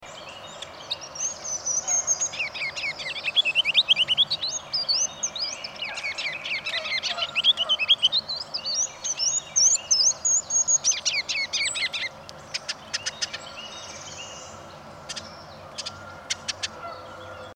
Here’s the ruby’s song and, at the end, the “chack” he makes when annoyed.
Genre: Regulidae.